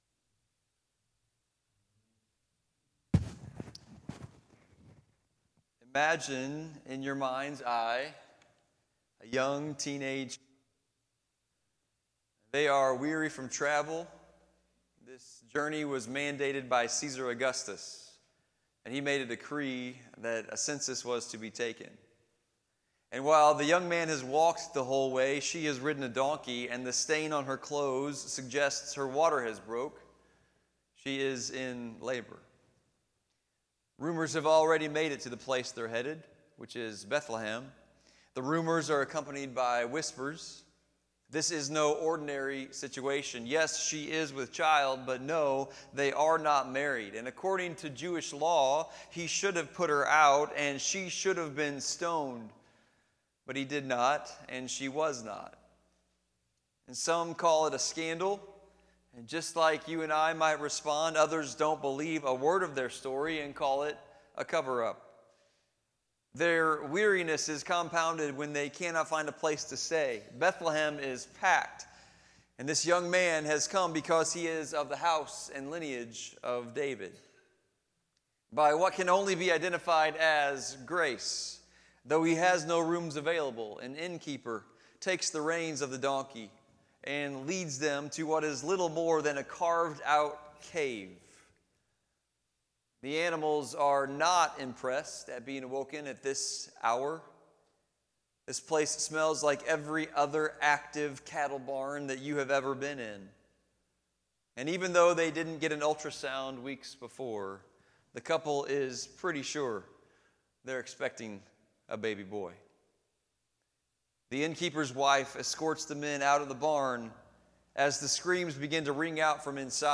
FBC Potosi - Sunday Service